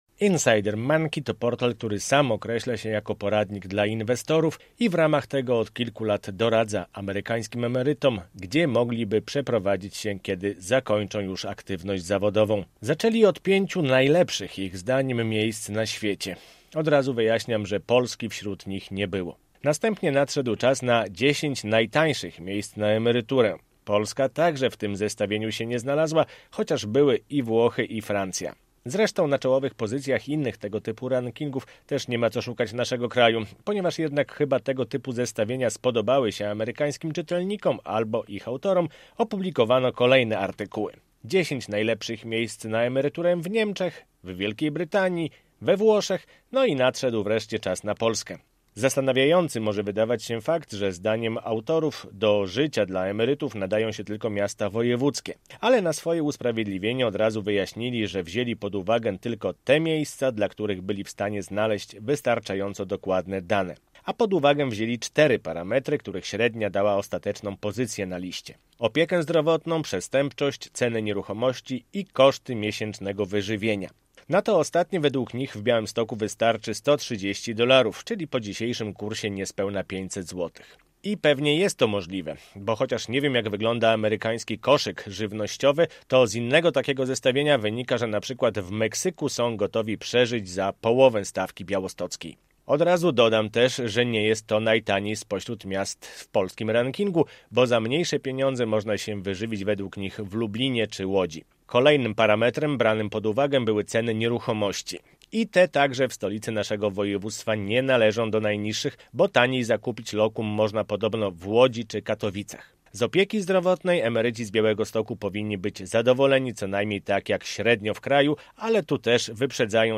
Białystok najlepszy dla emerytów - komentarz